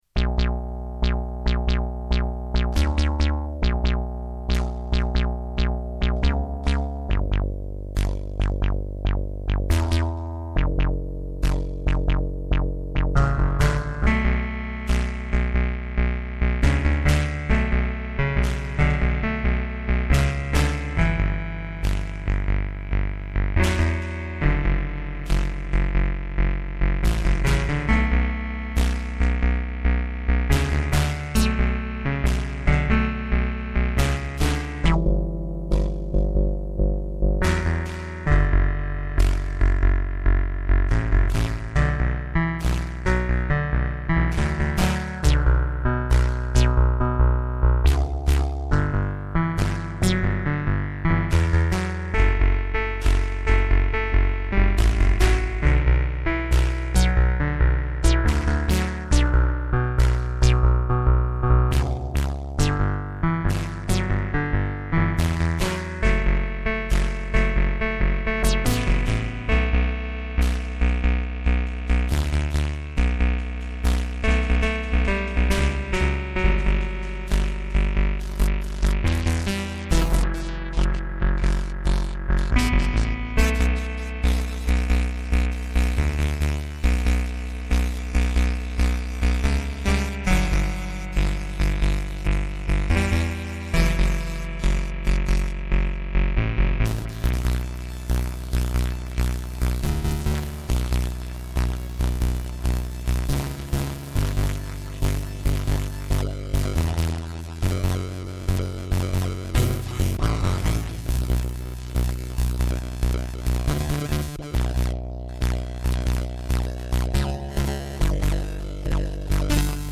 Poly800 plays bass,VCF is modulated by hi-hat and other sounds from K1.I have wrong SEQ speed pot,that`s why so slowly...1.9 MB
poly800-K1Modulated.mp3